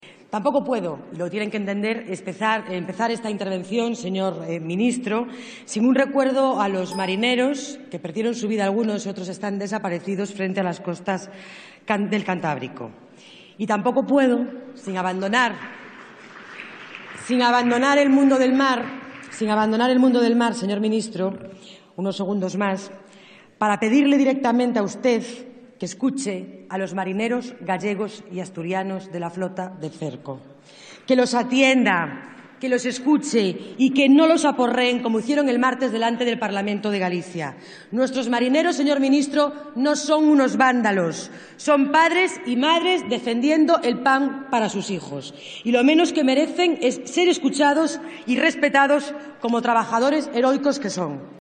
Laura Seara en el Congreso rinde homenaje a los pescadores fallecidos en Galicia y a los que luchan por las cuotas 13/03/2014